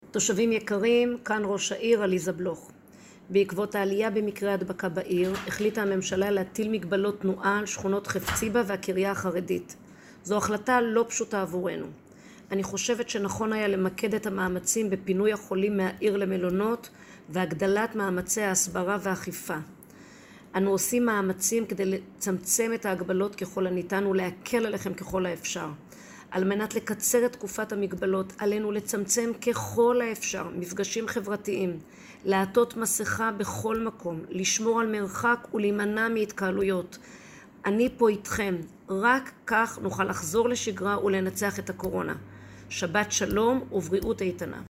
הודעת ראש העיר ד"ר עליזה בלוך
הודעת-ראש-העיר-עליזה-בלוך-לתושבי-בית-שמש-1.mp3